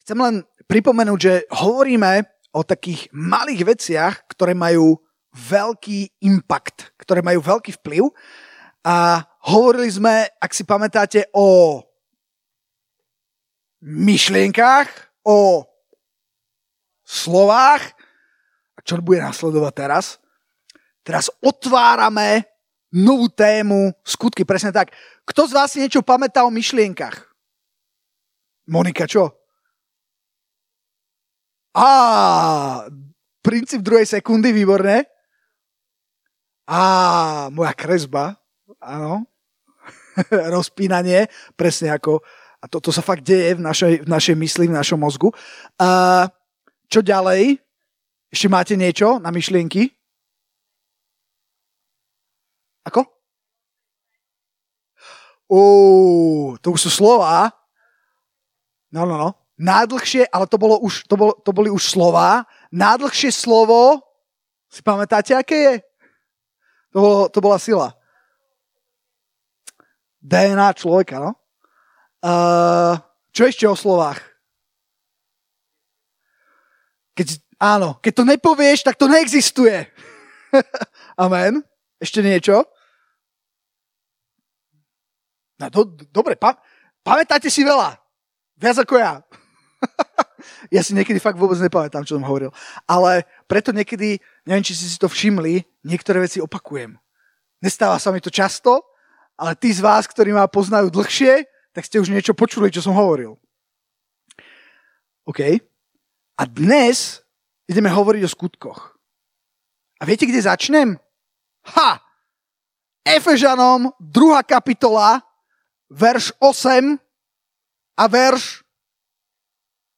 Nahrávky zo stretnutí mládeže Slova života Bratislava